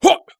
人声采集素材/男3战士型/ZS发力8.wav